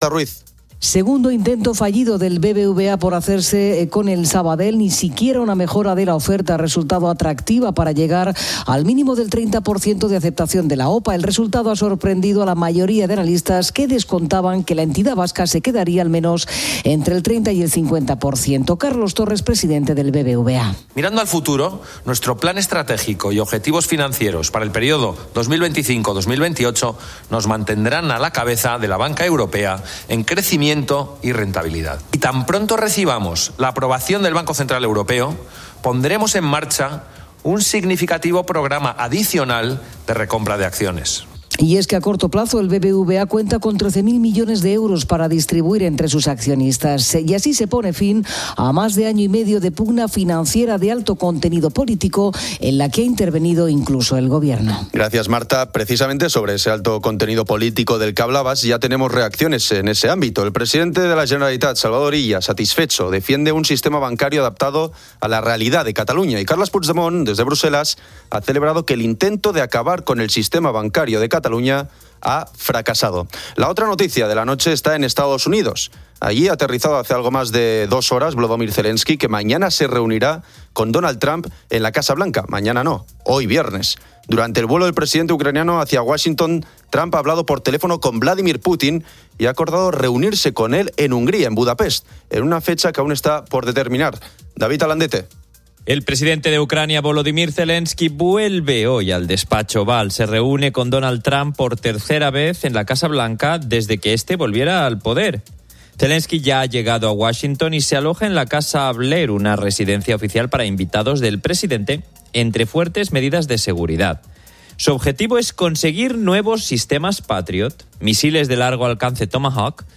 En COPE, "Poniendo las Calles" emite desde Eslovaquia, donde España lidera una brigada de la OTAN, destacando los valores y el compañerismo militar.